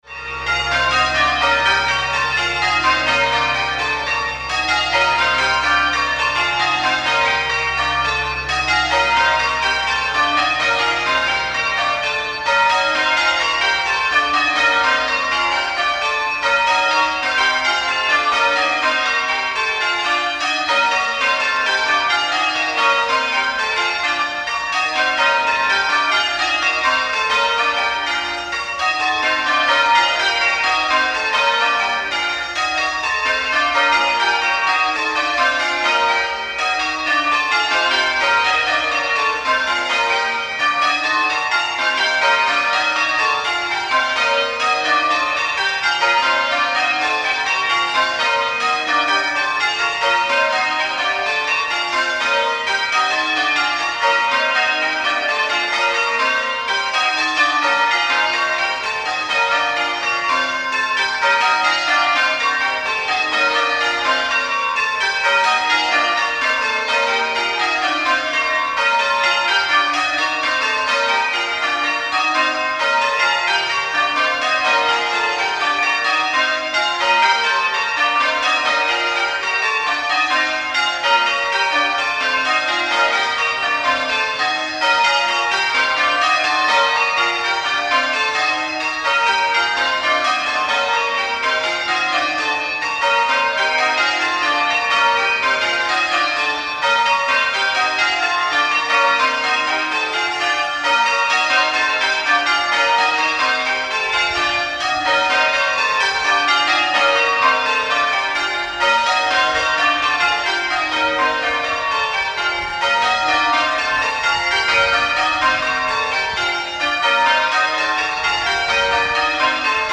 8 bells 8-0-2 in B♭ Excellent going order
Extract from a peal of Cambridge Surprise Major, 23rd May 2025